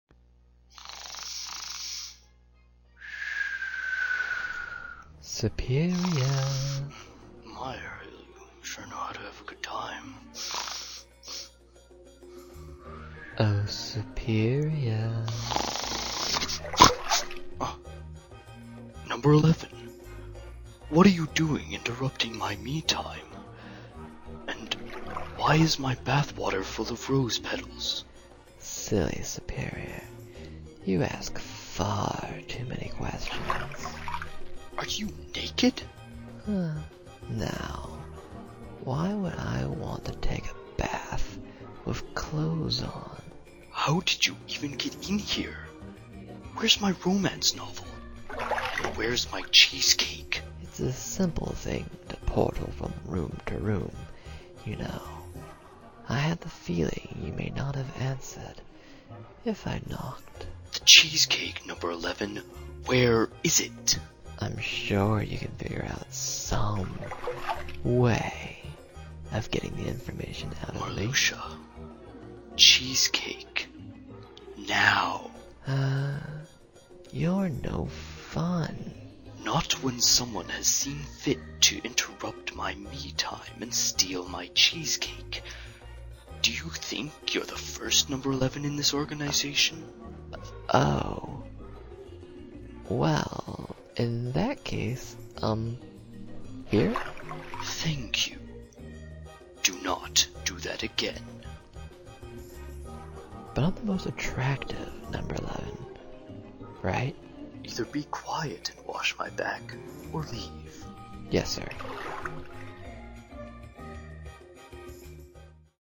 Short Dramas